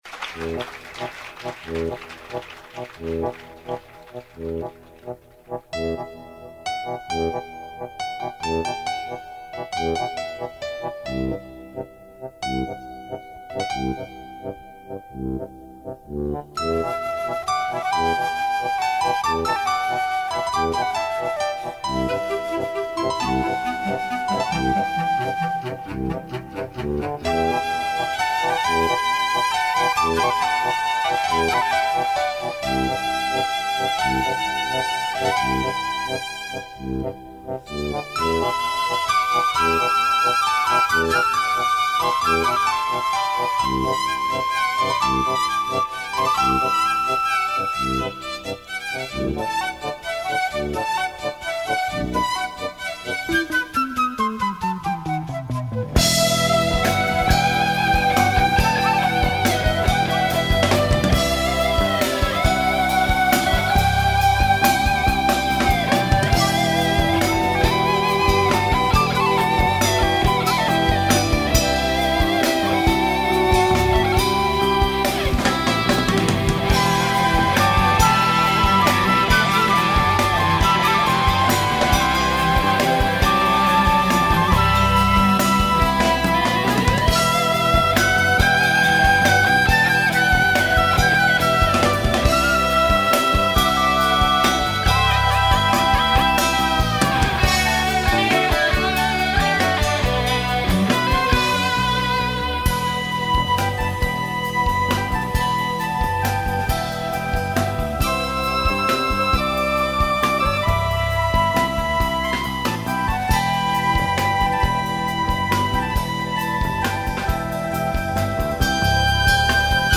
Doppel-Live-Album